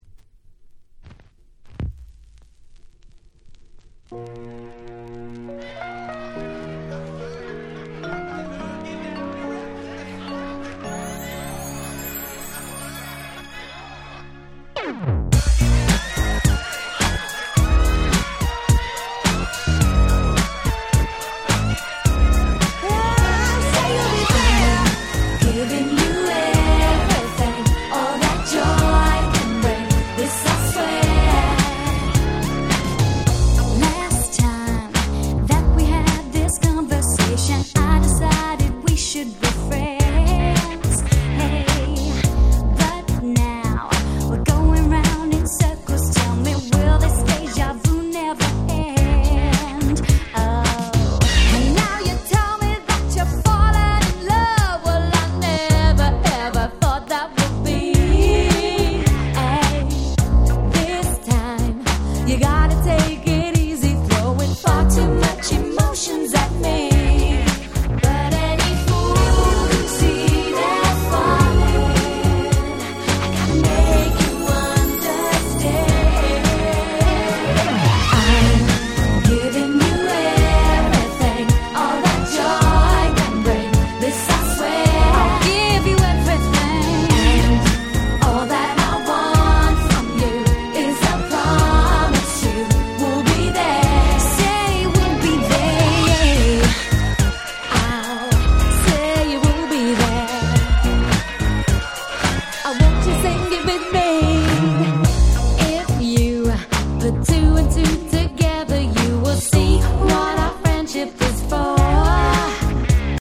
【Media】Vinyl 12'' Single
96' Super Hit R&B !!